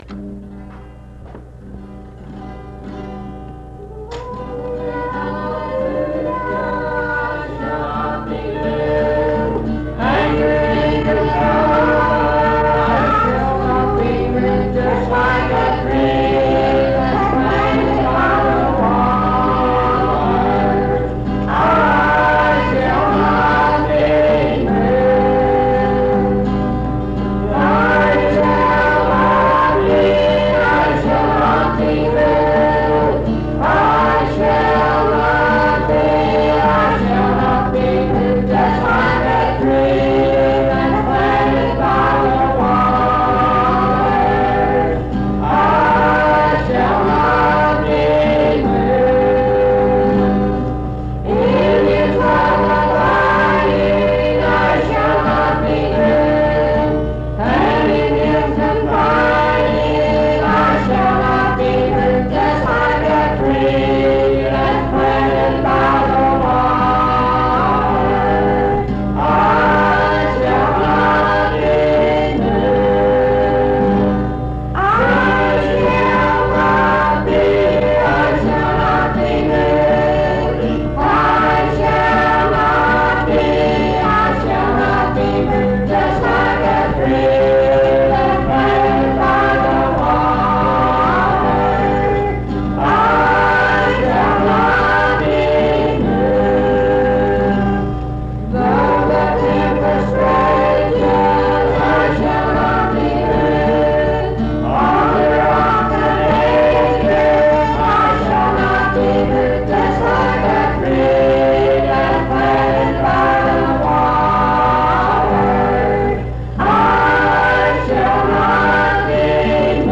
Highland Park Methodist Church, Morgantown, Monongalia County, WV.